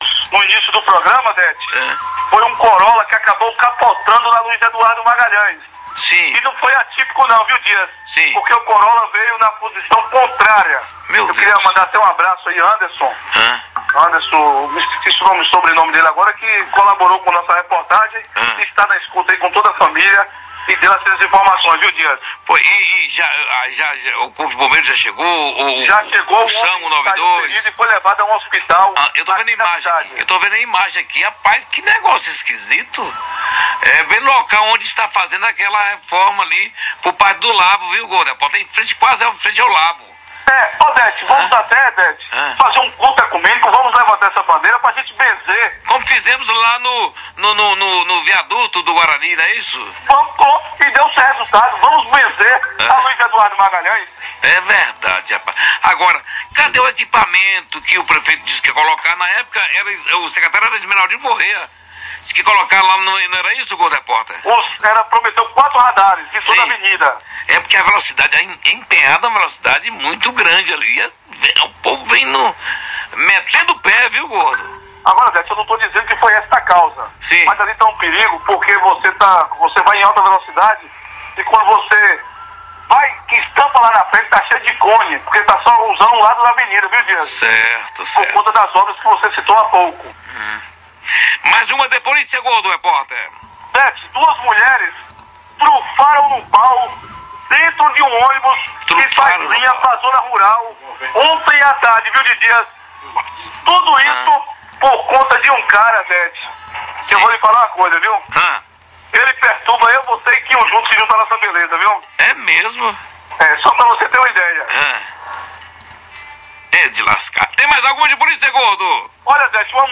Conquista: Mulheres saem no tapa dentro de ônibus, confira no plantão policial